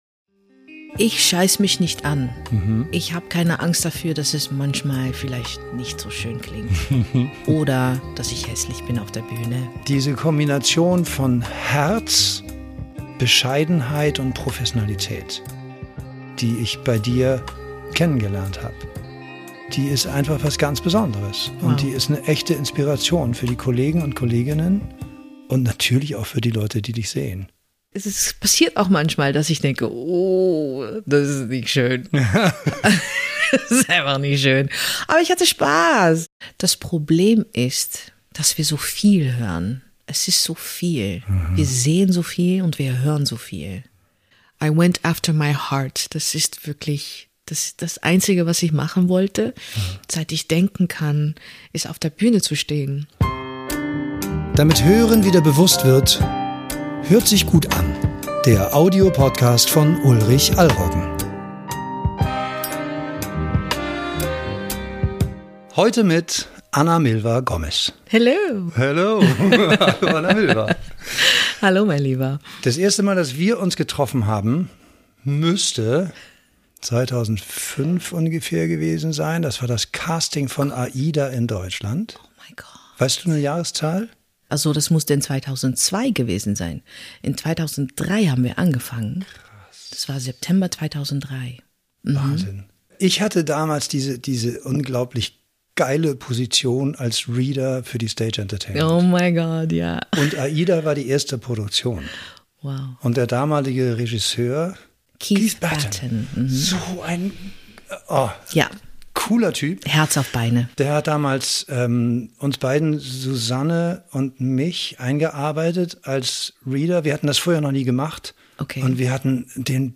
Viel Freude mit diesem sehr persönlichen Gespräch.